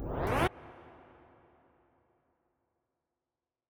56 Nights Drop FX.wav